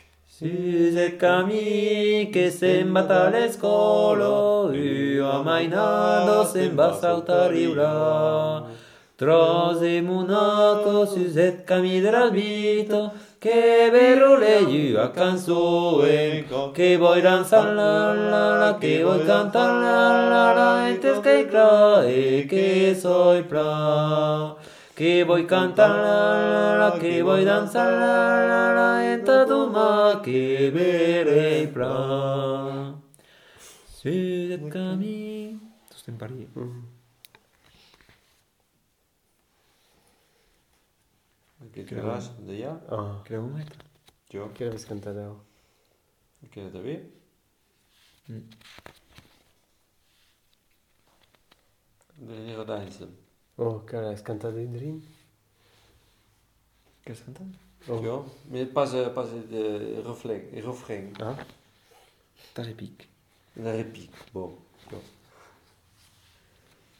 Aire culturelle : Bigorre
Lieu : Bénac
Genre : chant
Effectif : 2
Type de voix : voix d'homme
Production du son : chanté ; fredonné
Descripteurs : polyphonie